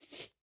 Minecraft Version Minecraft Version 25w18a Latest Release | Latest Snapshot 25w18a / assets / minecraft / sounds / mob / armadillo / ambient4.ogg Compare With Compare With Latest Release | Latest Snapshot